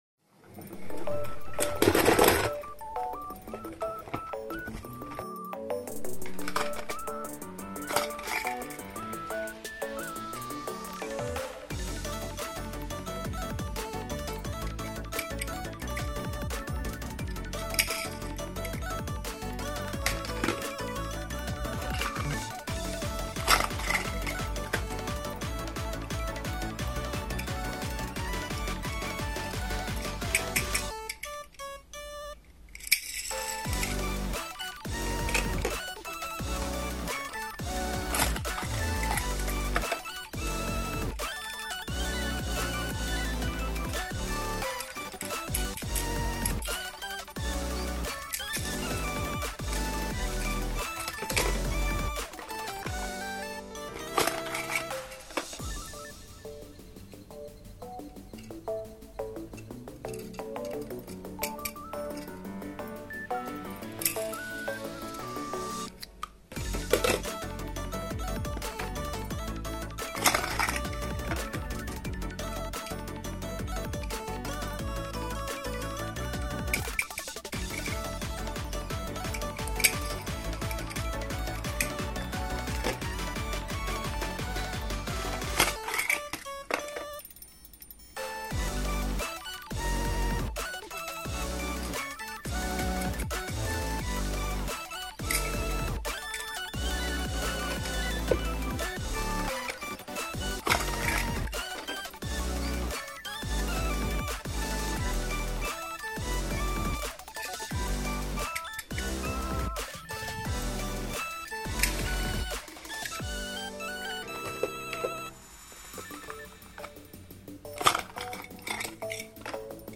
Disney Pixar Cars Red Convoy Sound Effects Free Download